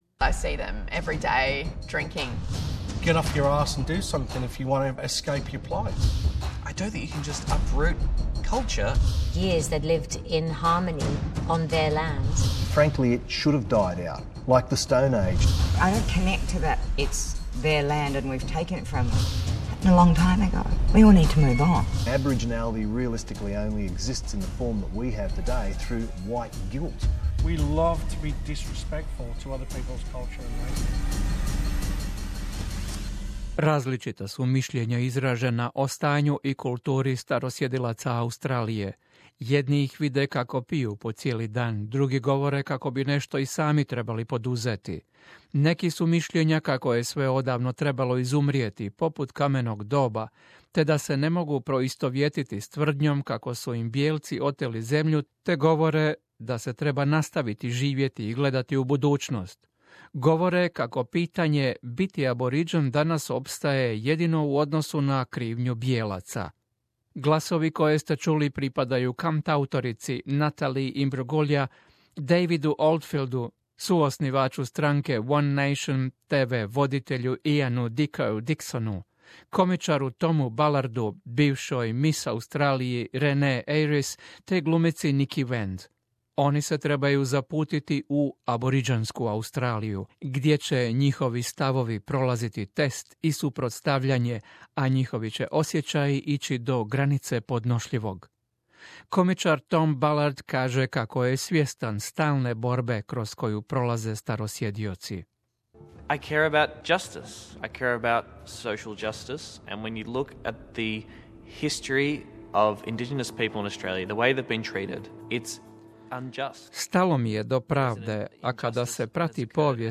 Aborigini i žitelji otočja Torresovog tjesnaca upozoreni su kako u prilogu koji slijedi mogu čuti glasove i imena preminulih osoba. Prve emisije TV serije First Contact pokrenule su po prvi puta široku društvenu raspravu o aboriginskoj Australiji.